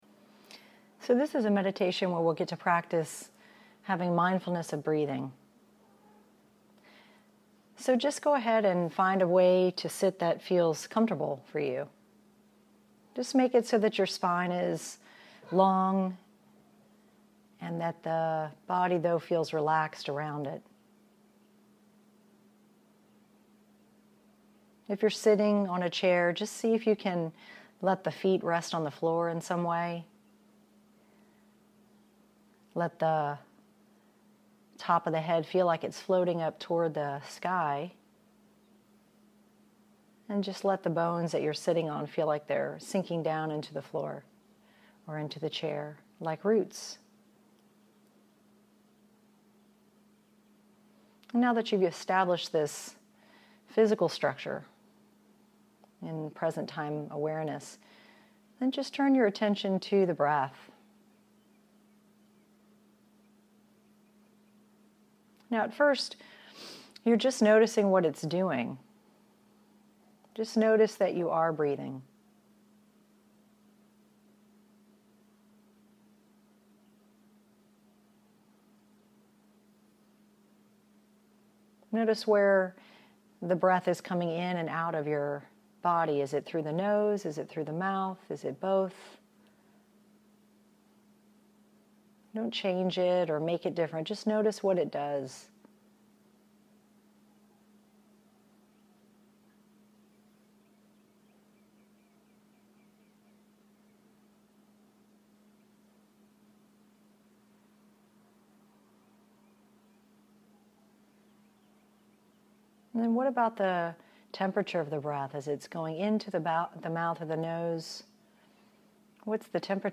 This is a 20-minute guided meditation practice where we focus on experiencing mindfulness of breathing.